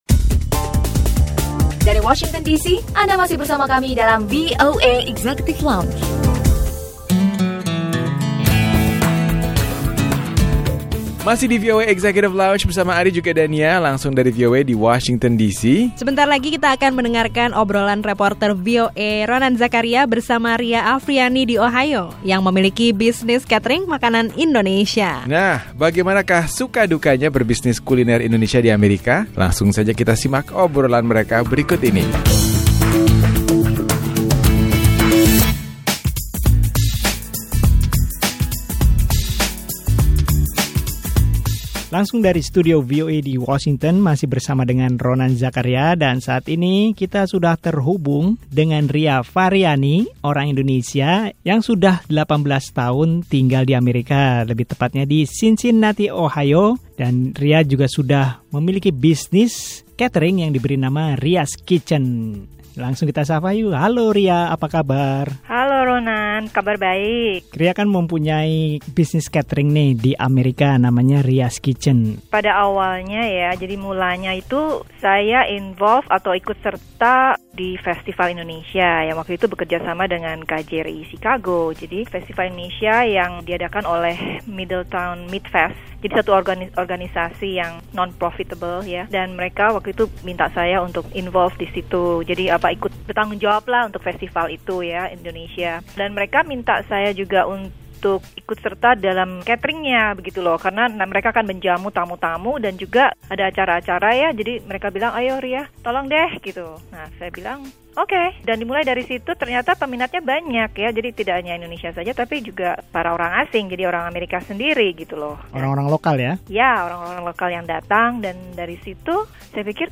Obrolan VOA